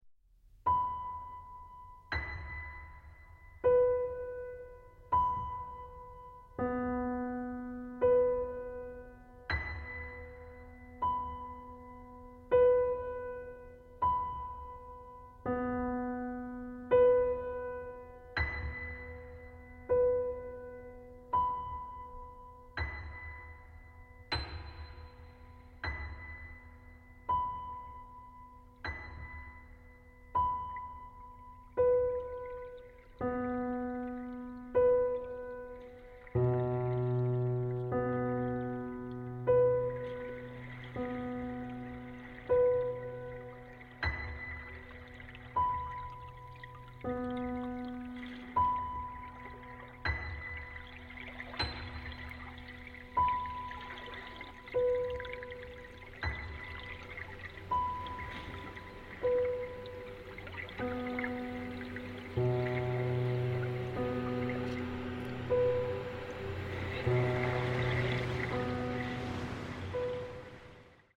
for solo piano, transducers, and field recordings